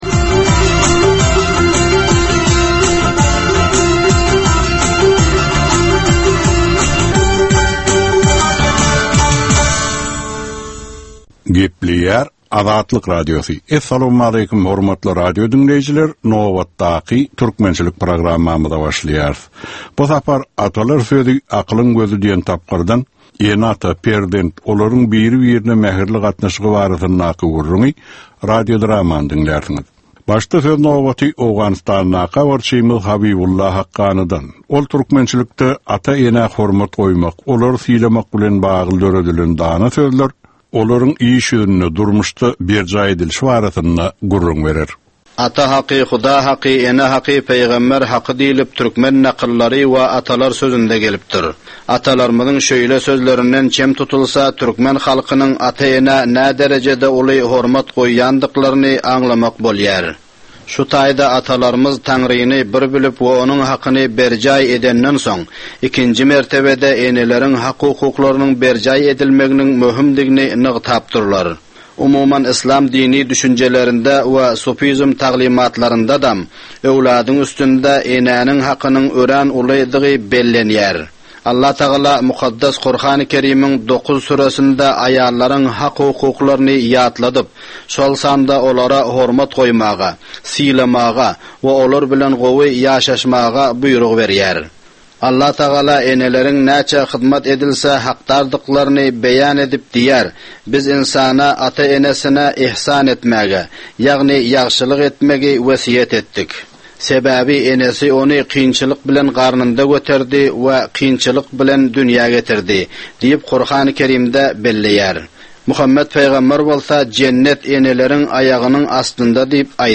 Türkmen halkynyň däp-dessurlary we olaryň dürli meseleleri barada 10 minutlyk ýörite gepleşik. Bu programmanyň dowamynda türkmen jemgyýetiniň şu günki meseleleri barada taýýarlanylan radio-dramalar hem efire berilýär.